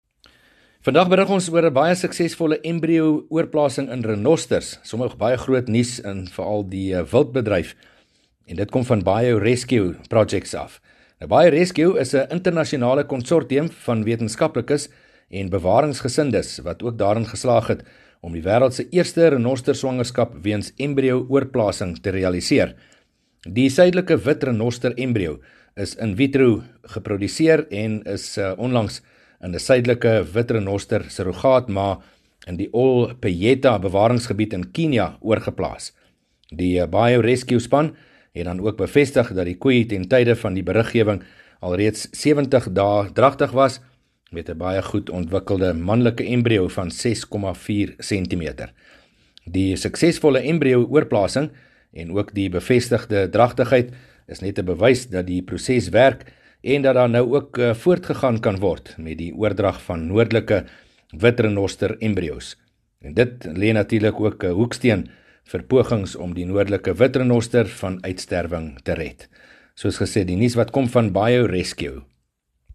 Landbou Insetsels 4 Apr Seldsame embrio oorplasing 1 MIN Download